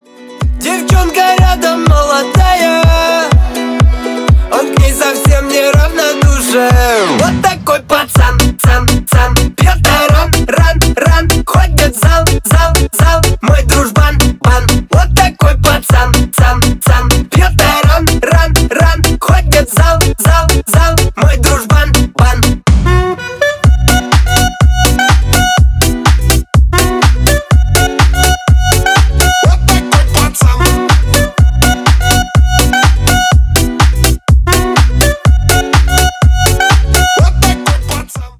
Поп Музыка
клубные # весёлые